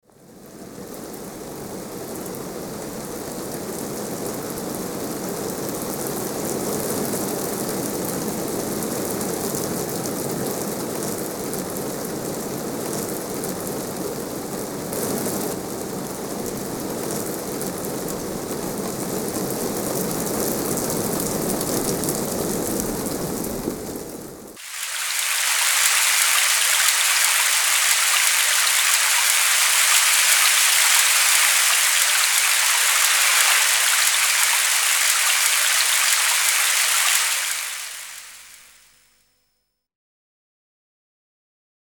Porozmawiajcie z dziećmi na temat wiersza i posłuchajcie nagrań wiatru i deszczu (zagadki słuchowe)
Odglosy-deszczu.mp3